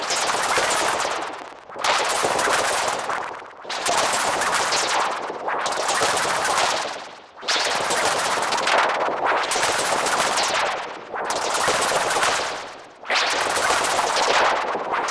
• Sewer Texture Glitches.wav